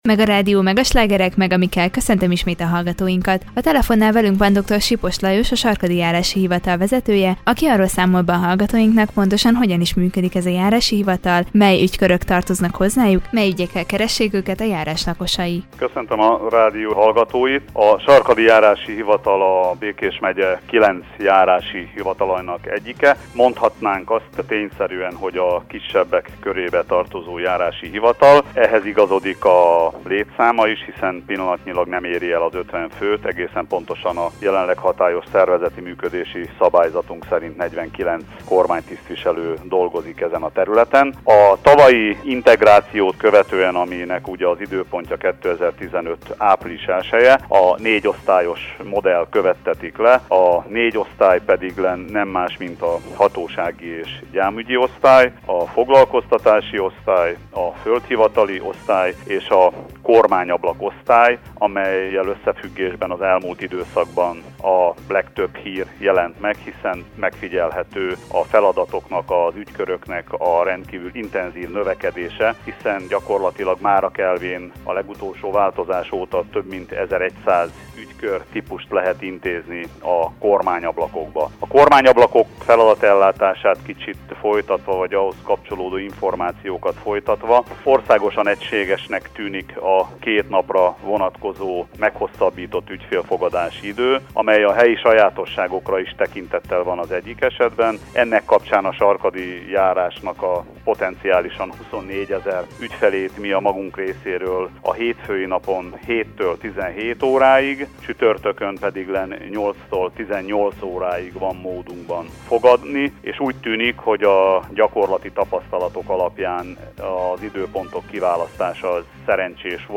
A Sarkadi Járási Hivatal vezetőjével, Dr. Sipos Lajossal beszélgetett tudósítónk a Járási Hivatal működéséről, a hivatalhoz tartozó ügykörökről valamint az illetékességi területről is.